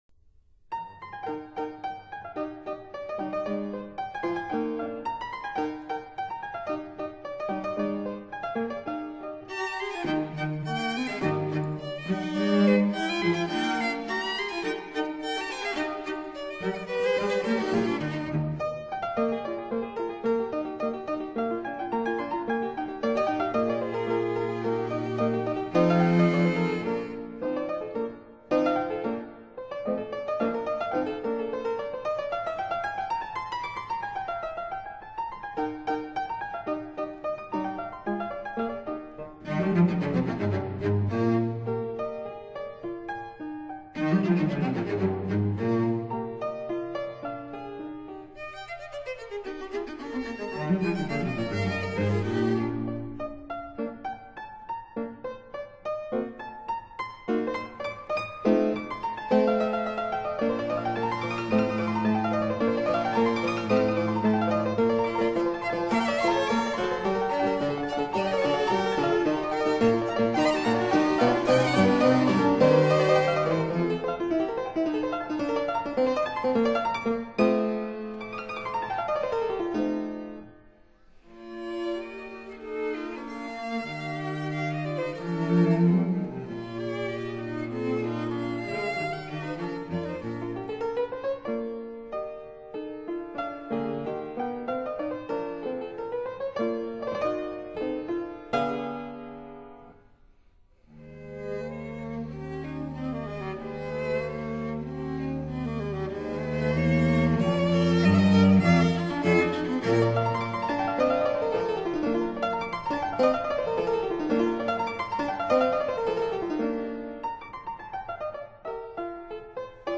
Piano Quartet in E-flat major
Allegretto    [0:08:27.67]